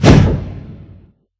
Punch Swing_HL_4.wav